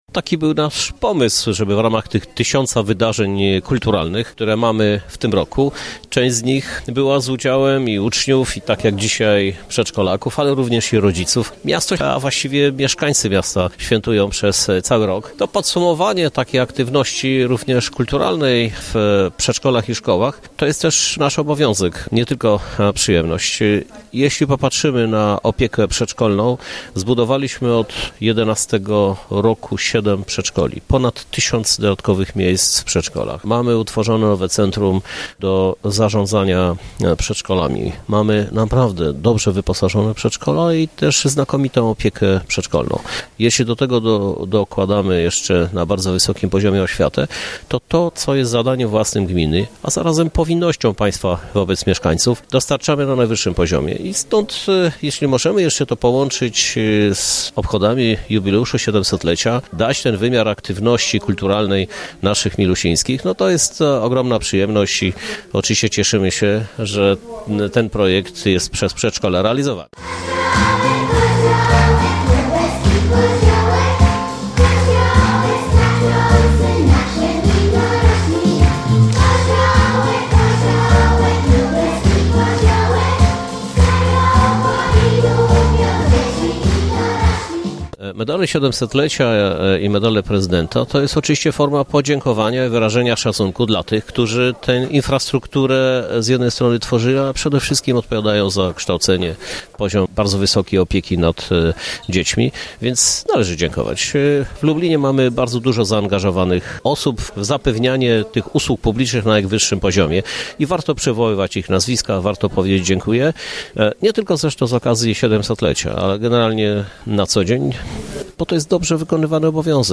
Prezydent Krzysztof Żuk mówi, że osiągnięto już dużo, jednak do zrobienia pozostało jeszcze wiele rzeczy. Wśród najważniejszych jest modernizacja przedszkoli, ale także wyposażenie ich w odpowiedni sposób.